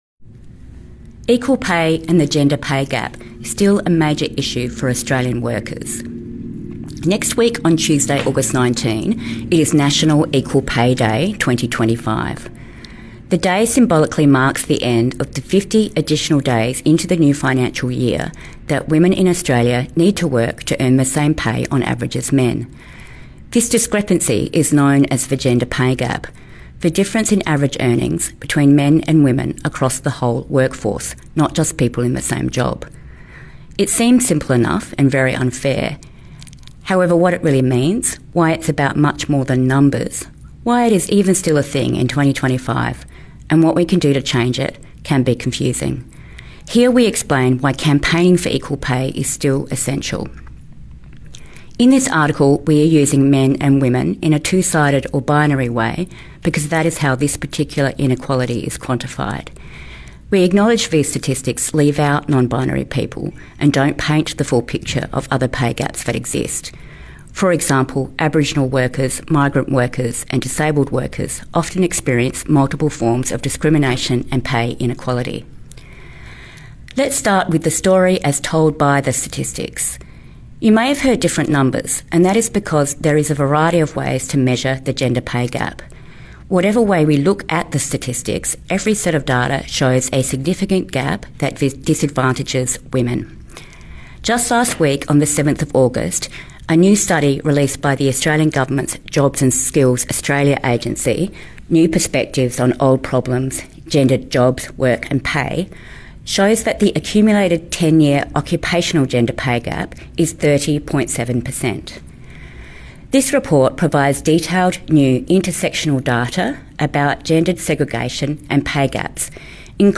A screen reading of this article takes around 5 minutes or you can listen to it here: